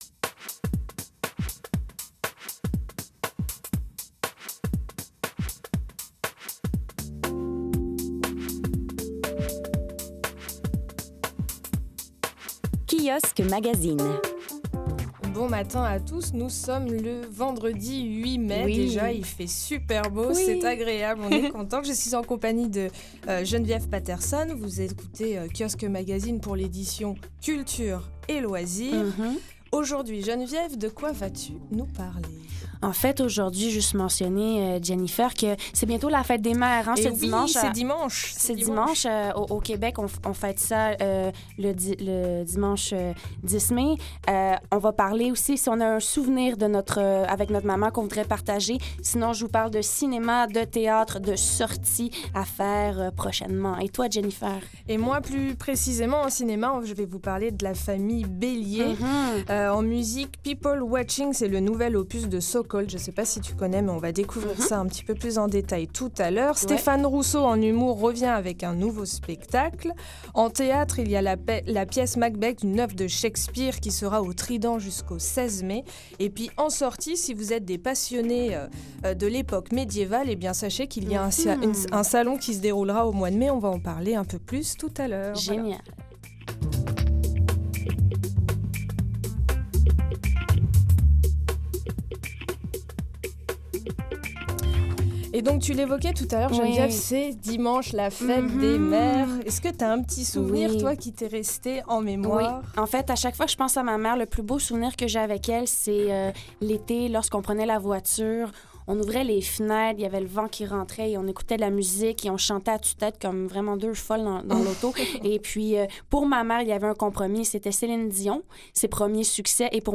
Aujourd'hui, le meilleur des magazines culture et loisirs sous forme de revue de presse